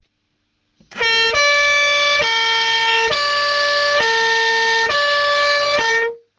Fisa avertisseur Impulsound 30 FPF2C 12V | 146195
Fisa avertisseur Impulsound 30 FPF2C 12VSpécifications:- 118dB- 200W- 560Hz high tone- 460Hz low tone- 30 cycles par minute